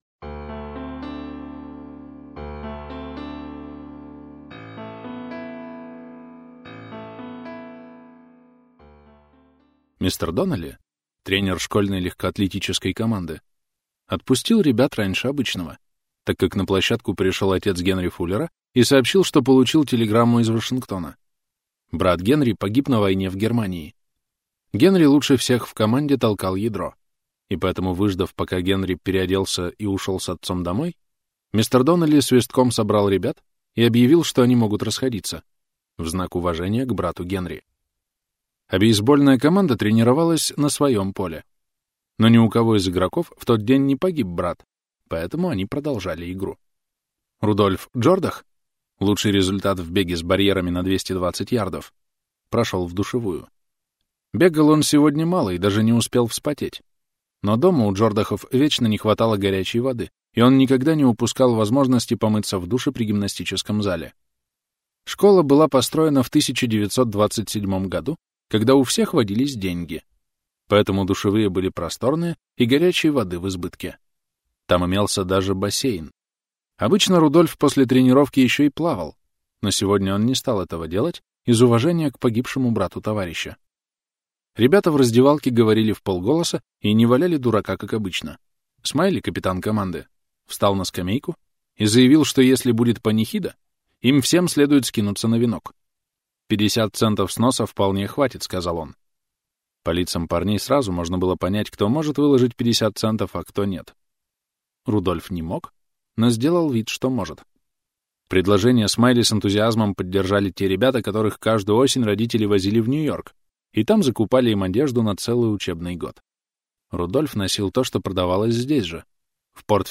Аудиокнига Богач, бедняк - купить, скачать и слушать онлайн | КнигоПоиск